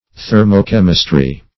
Thermochemistry \Ther`mo*chem"is*try\, n. [Thermo- + chemistry.]